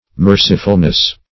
Mer"ci*ful*ness, n.